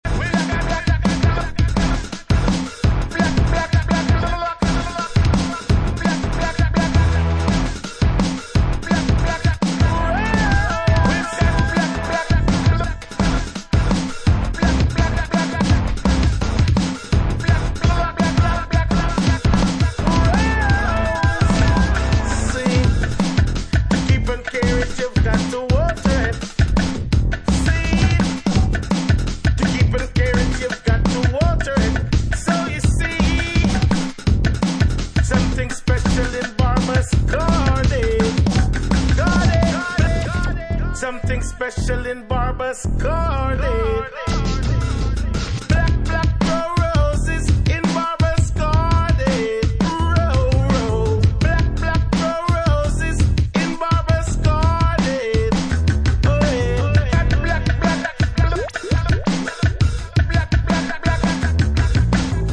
UK Garage / Breaks, Hip Hop/Dj Tools